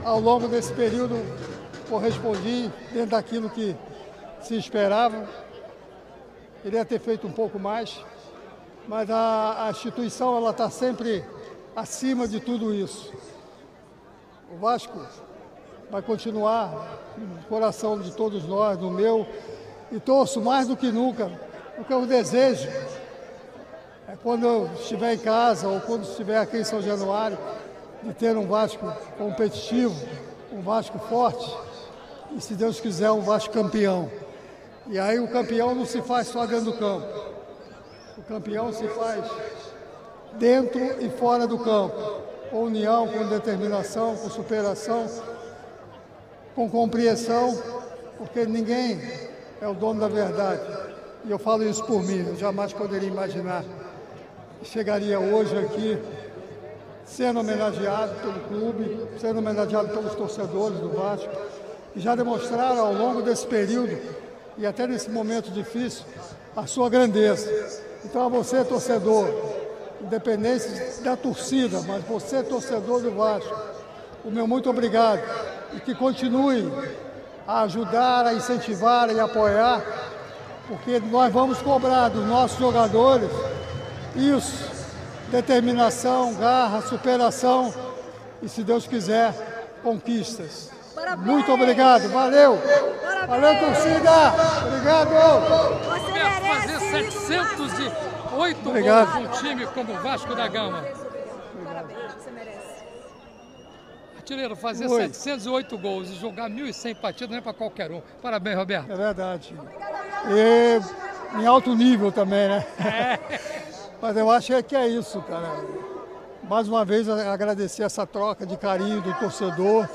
Ao microfone ele agradeceu a homenagem e desejou dias de glórias ao Vasco de hoje e amanhã.